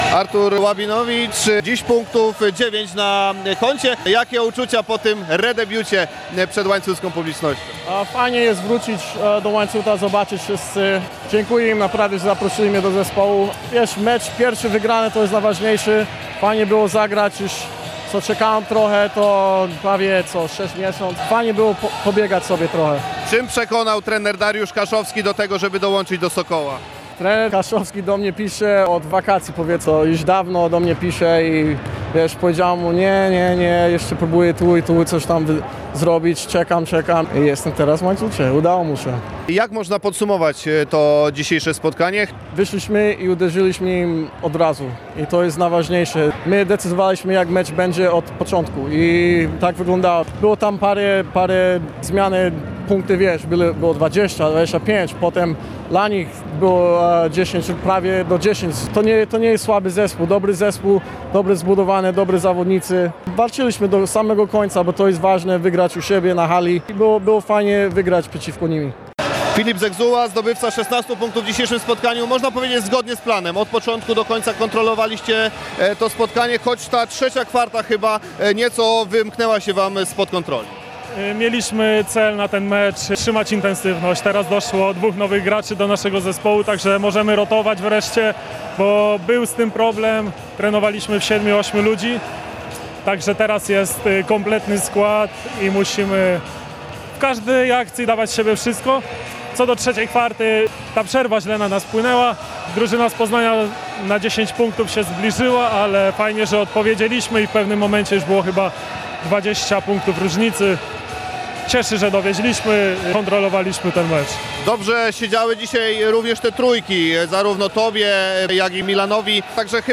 lancut-po-poznaniu.mp3